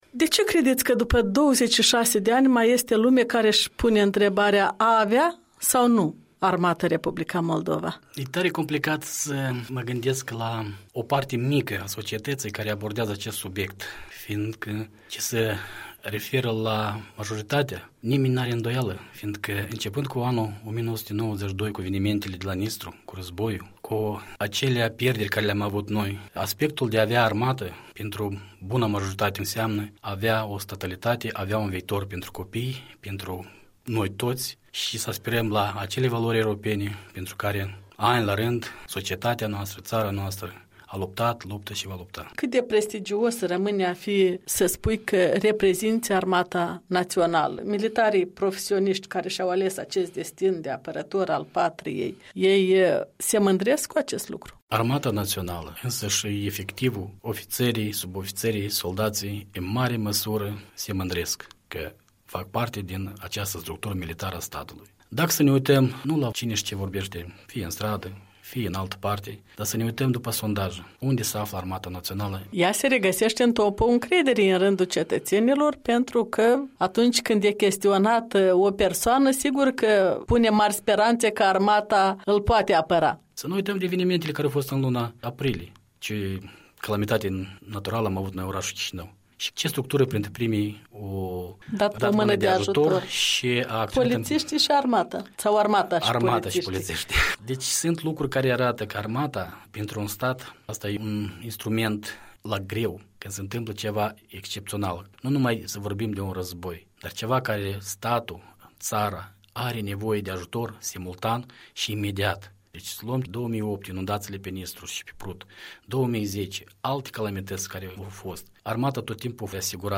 O discuție cu ocazia Zilei Armatei Naționale a Republicii Moldova.